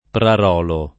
[ prar 0 lo ]